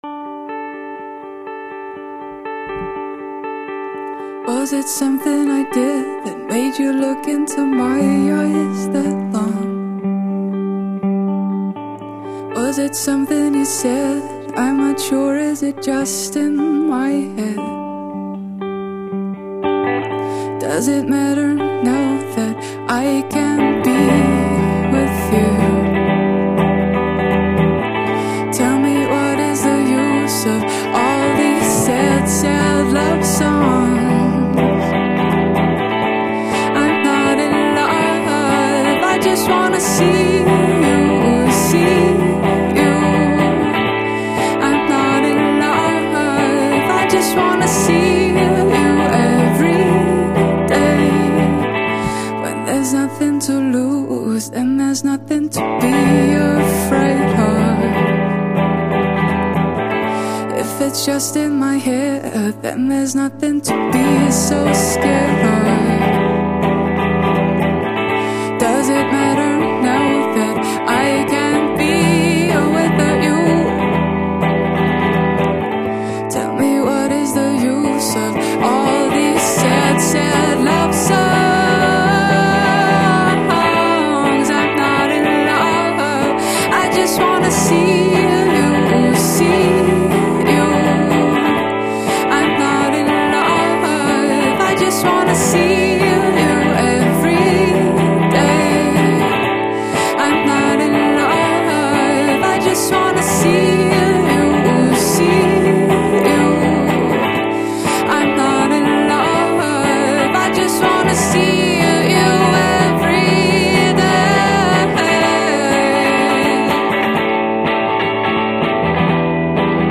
im on3-Studio